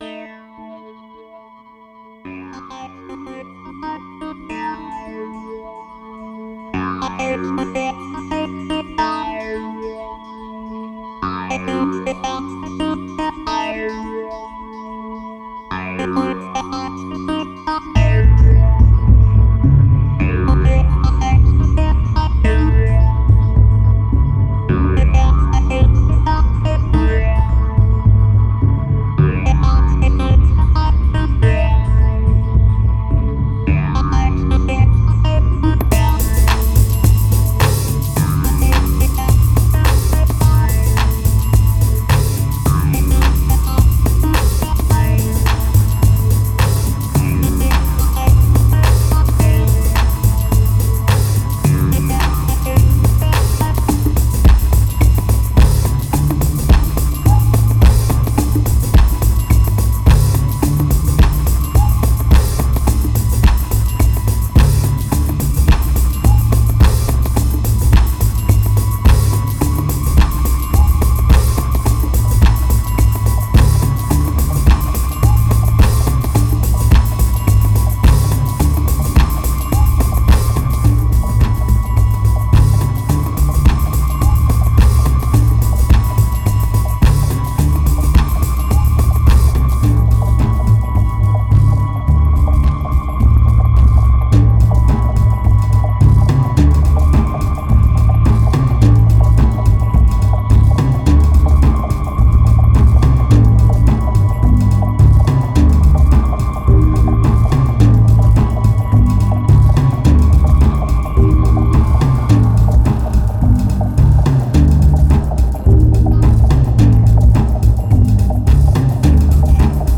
2214📈 - -6%🤔 - 107BPM🔊 - 2011-04-09📅 - -220🌟